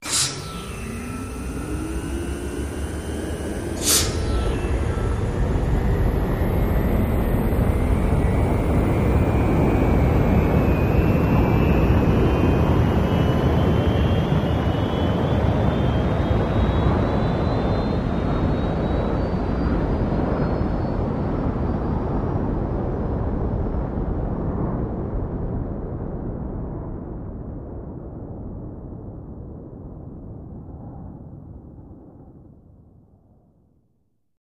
Rocket, Take Off & Away 1